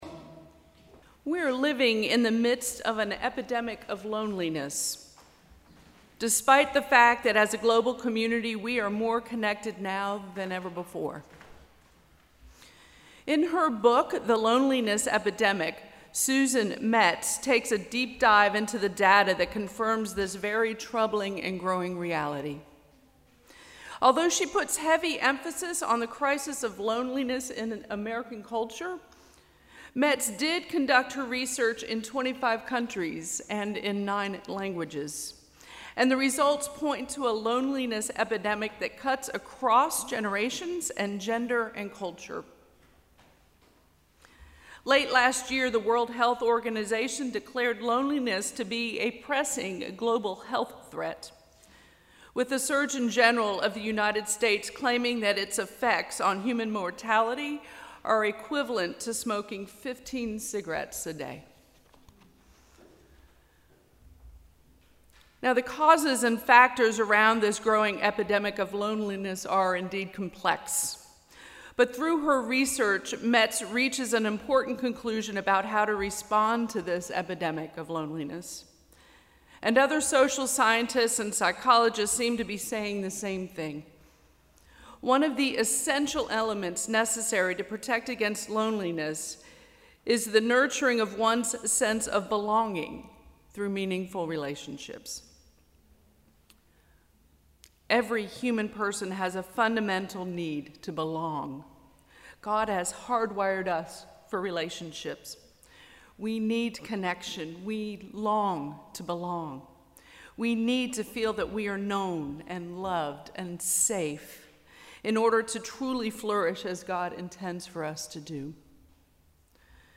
Sermon: ‘Fully Known, Fully Loved’